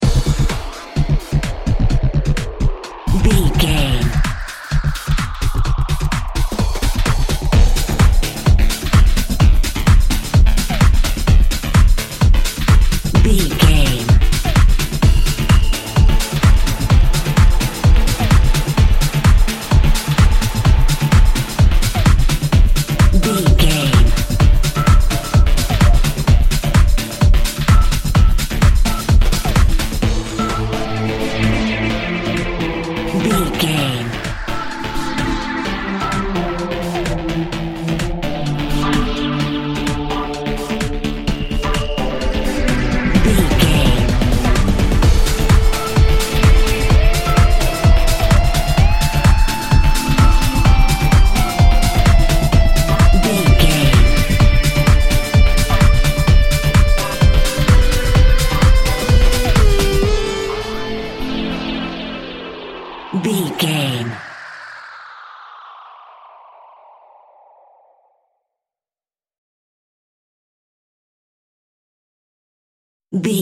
Aeolian/Minor
Fast
driving
energetic
hypnotic
frantic
synthesiser
drum machine
breakbeat
power rock
synth leads
synth bass